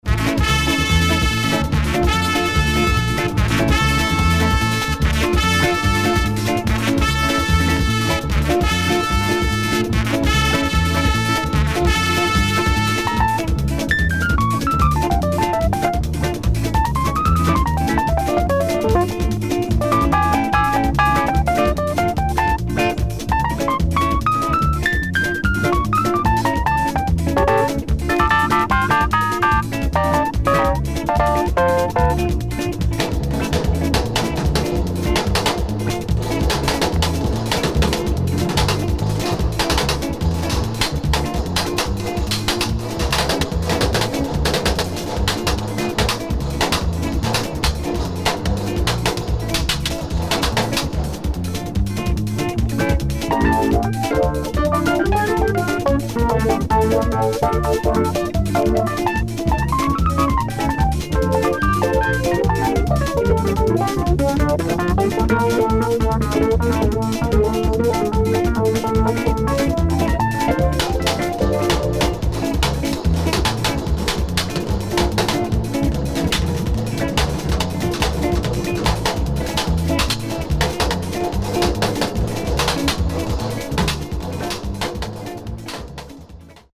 calypso groove
with electric piano and percussion breaks